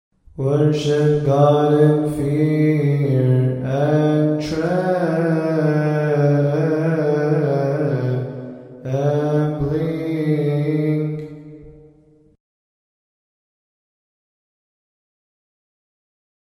All hymns must be chanted according to the Higher Institute of Coptic Studies.